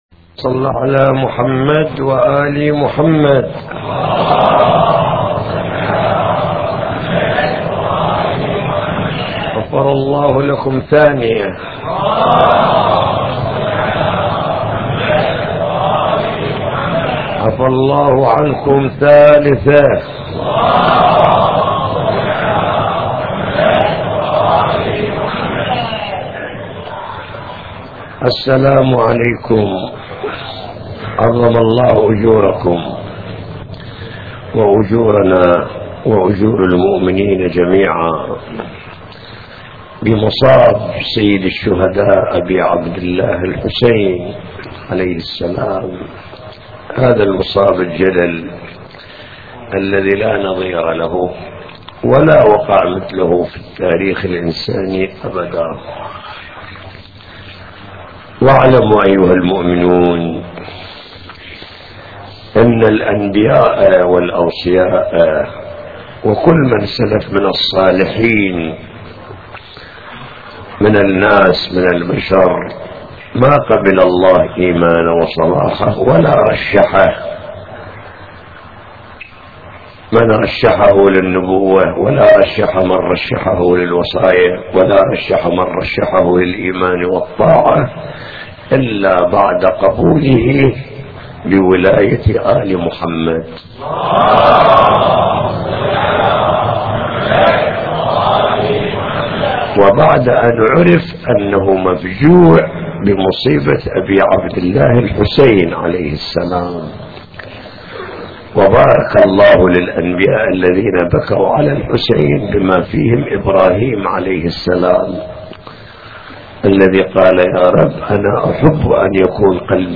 مواعظ حسينية